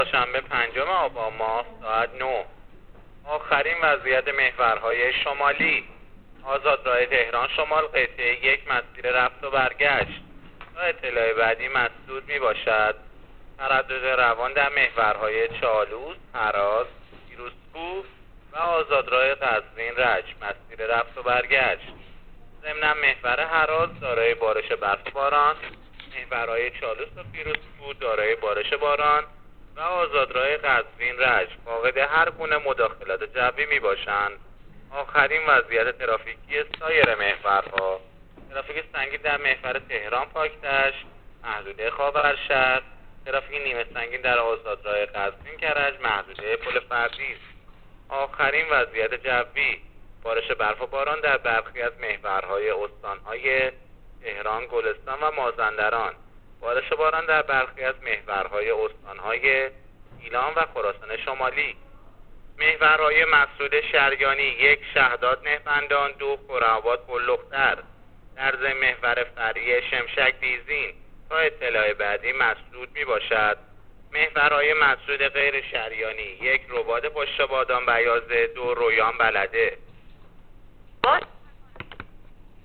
گزارش رادیو اینترنتی از آخرین وضعیت ترافیکی جاده‌ها تا ساعت ۹ پنجم آبان؛